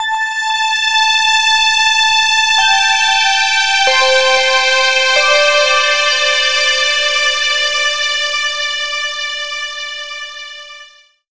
06 Old Frontier 170 Dmin.wav